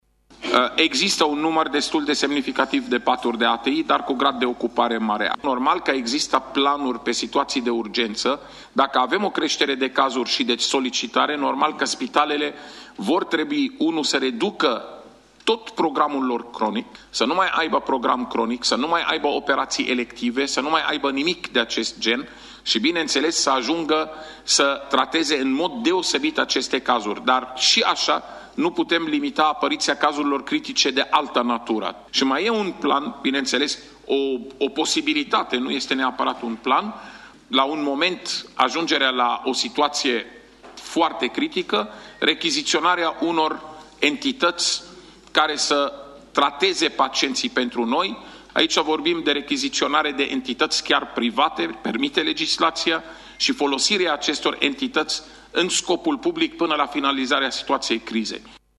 Statul ar putea apela chiar la medici privați pentru a da o mână de ajutor colegilor lor din spitale, a anunțat șeful DSU, dr. Raed Arafat, în cadrul audierii de azi în Comisia de Sănătate din Parlament: